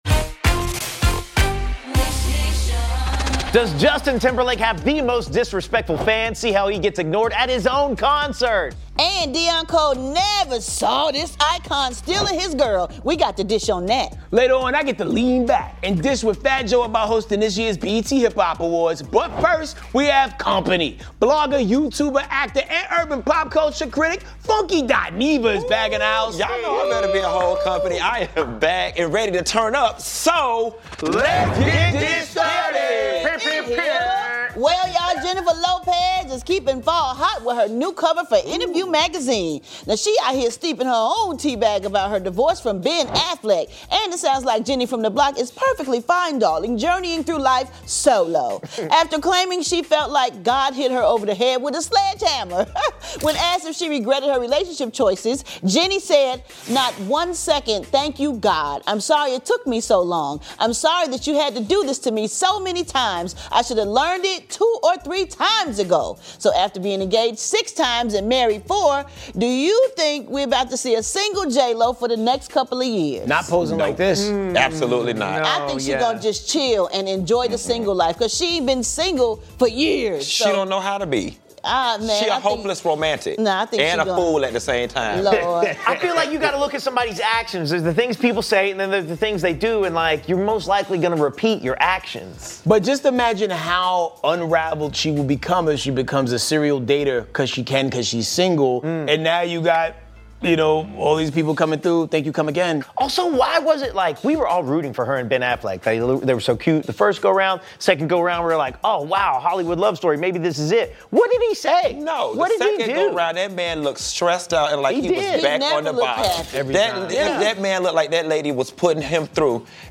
Season 13, Episode 25, Oct 11, 2024, 10:30 PM Headliner Embed Embed code See more options Share Facebook X Subscribe J Lo learned her lesson amid her divorce from Ben Affleck and is Stevie Wonder the real 'Mr. Steal Your Girl?' Deon Cole said so! Plus, Justin Timberlake serenades a distracted fan at his concert, and we dish with Fat Joe about the 2024 BET Hip Hop Awards.